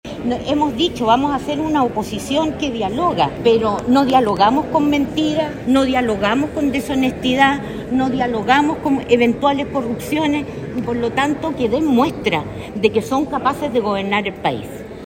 En esa línea, la diputada Lorena Fries (FA) indicó que su foco es ser una oposición que tienda puentes, sin embargo, dijo que eso no ocurrirá si lo que se busca es plantar suspicacias o directamente desinformar.